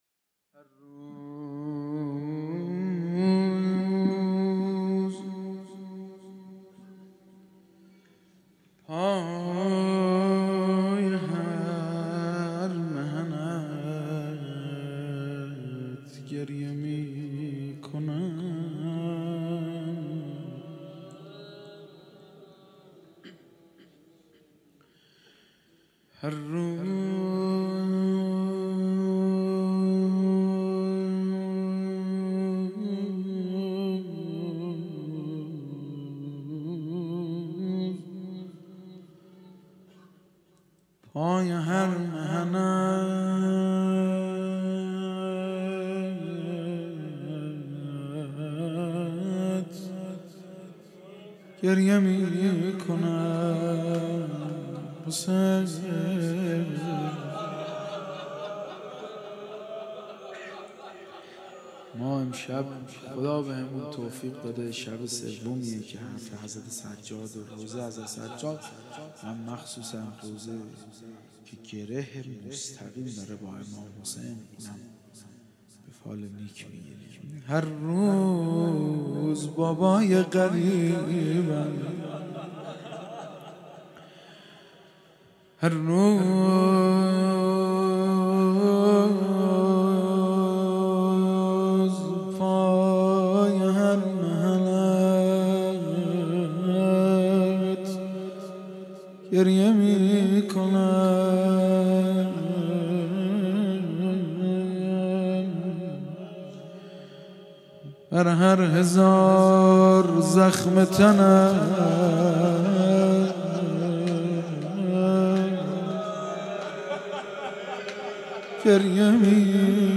مداحی
در مسجد کربلا برگزار گردید.